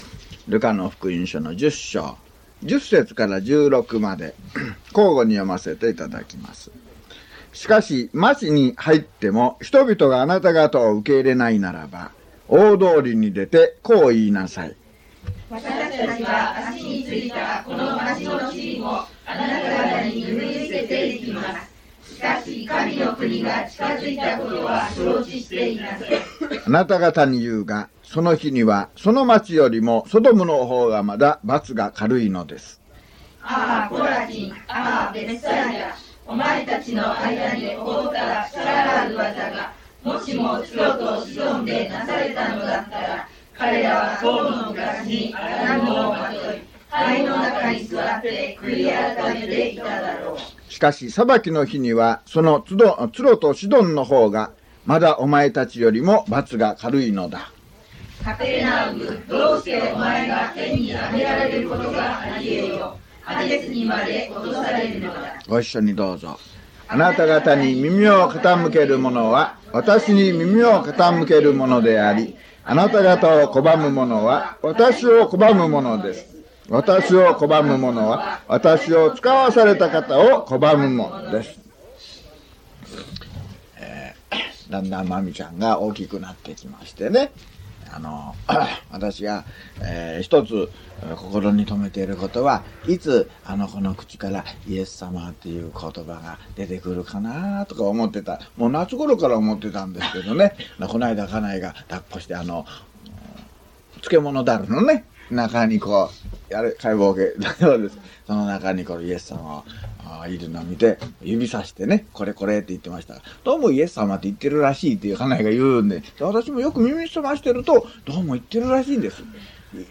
luke072mono.mp3